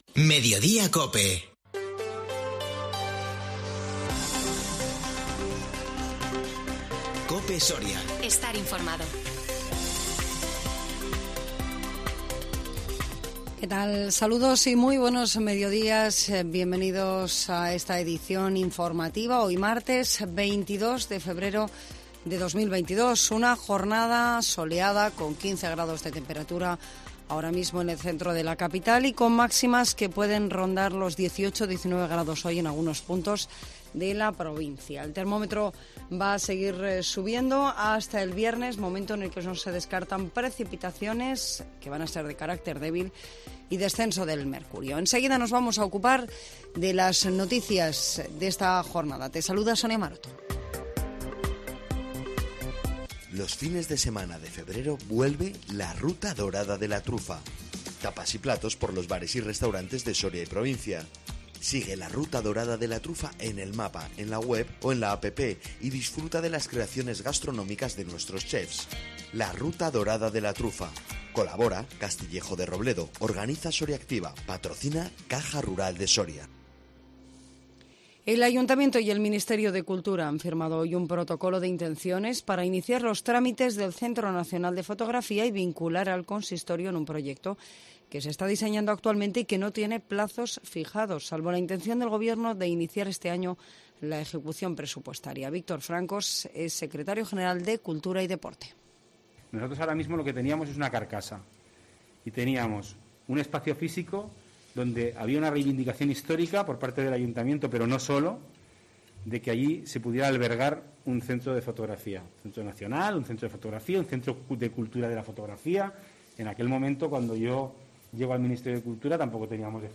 INFORMATIVO MEDIODÍA COPE SORIA 22 FEBRERO 2022